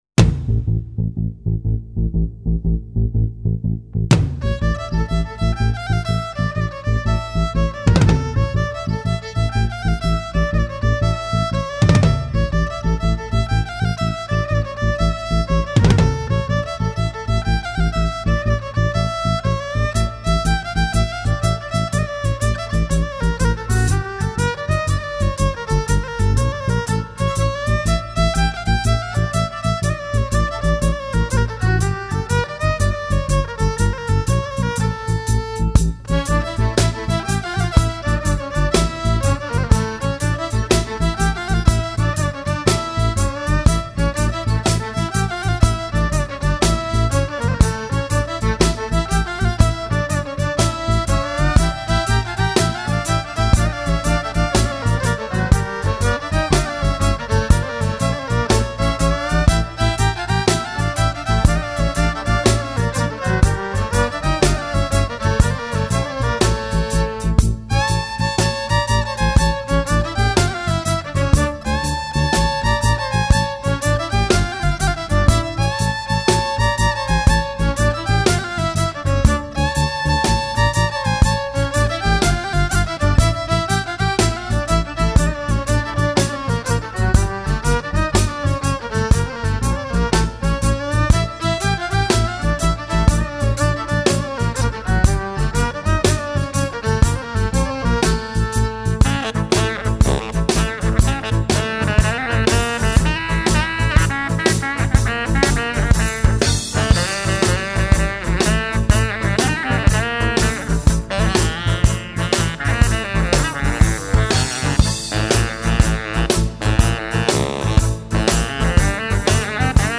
Celtic